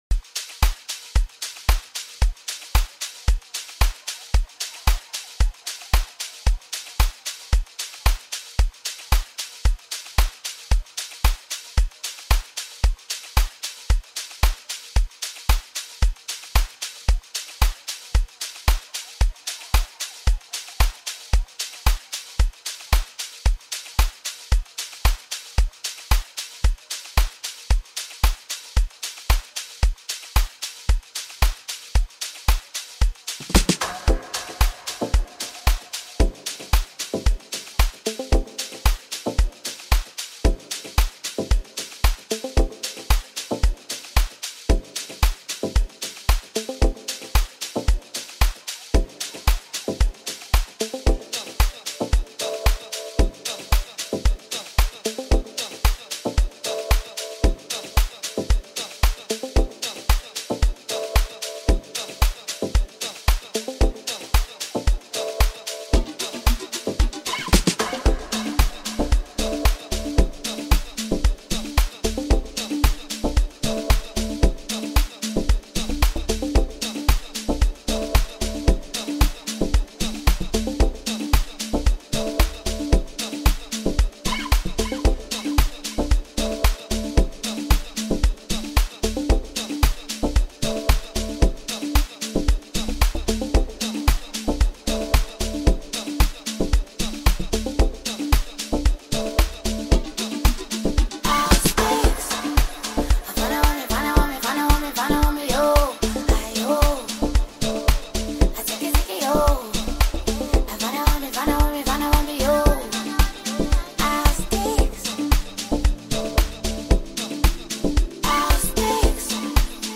Amapiano, DJ Mix, Hip Hop